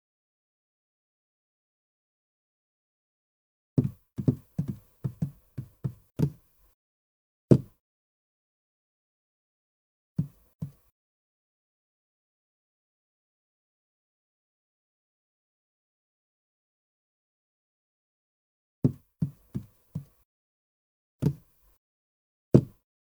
JGW_SC03_Dog2.ogg